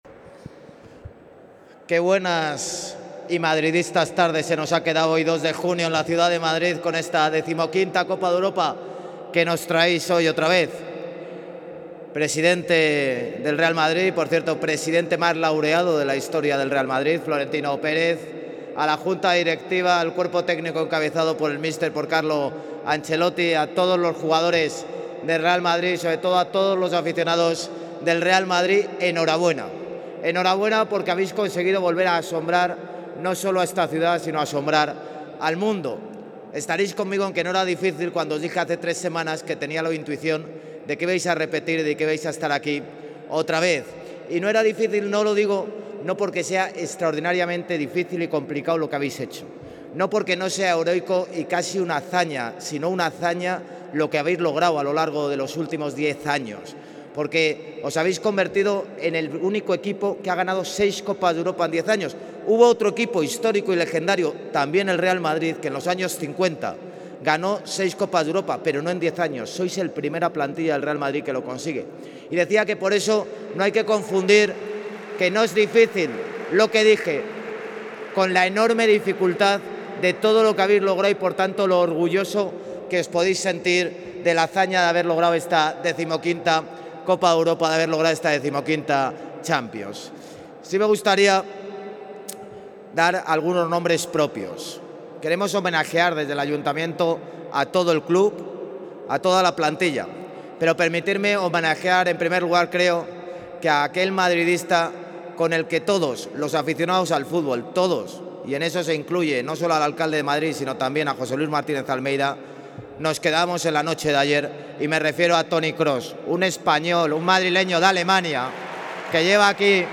Más archivos multimedia Intervención del alcalde de Madrid, José Luis Martínez-Almeida, en el acto de recibimiento en el Palacio de Cibeles al Real Madrid Club de Fútbol por su título de campeón de la Champions League 2024
(AUDIO) INTERVENCION ALCALDE CELEBRACION CHAMPIONS REAL MADRID 2024.mp3